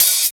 100 OP HAT.wav